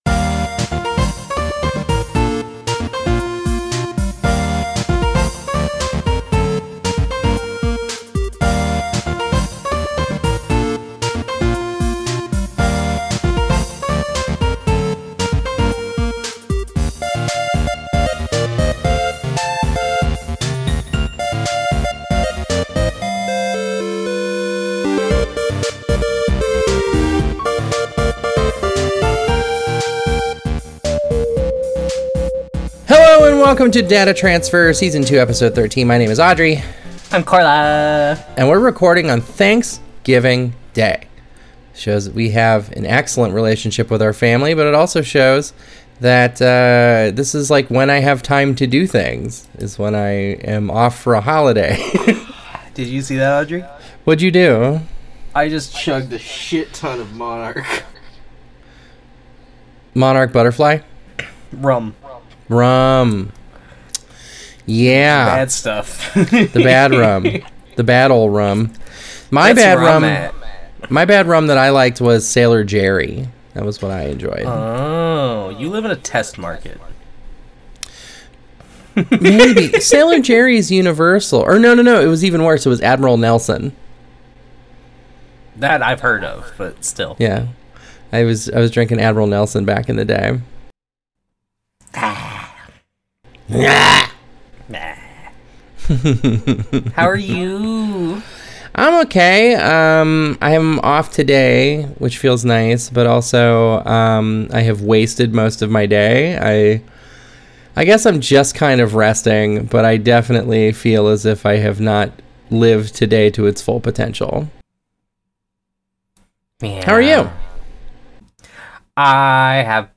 We recorded to celebrate the day and the party was really getting to both of our hosts, so it gets a lil' sloppy. It's also a fantastic episode of Digimon to go along with the unhinged energy!